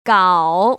[găo] 까오  ▶